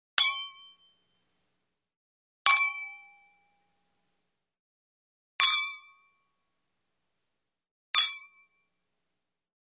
Звук дзынь
Самая банальная и первая приходящая на ум, это чоканье бокалами, стаканами или рюмками.
Чоканье бокалами: